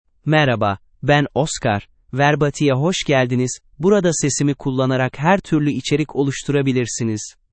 OscarMale Turkish AI voice
Oscar is a male AI voice for Turkish (Turkey).
Voice sample
Listen to Oscar's male Turkish voice.
Oscar delivers clear pronunciation with authentic Turkey Turkish intonation, making your content sound professionally produced.